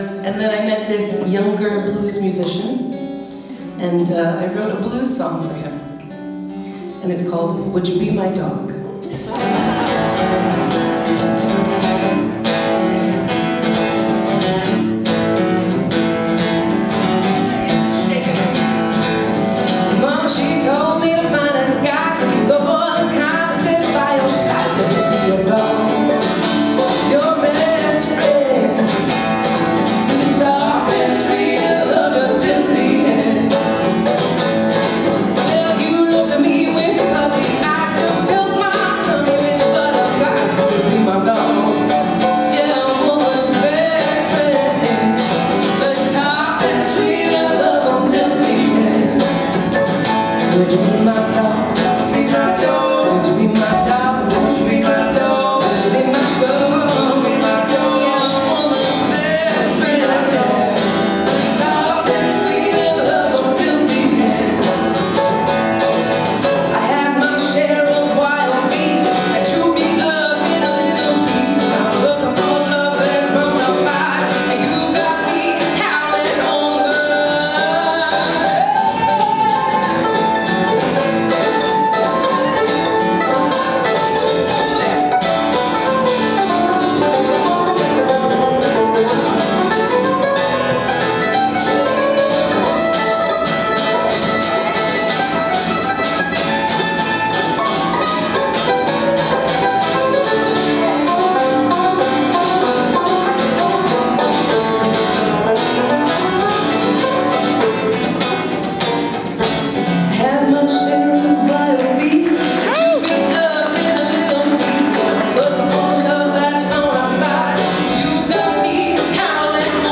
at Churchill High School
soul-infused ballads
guitar
keyboardist/mandolin
live recording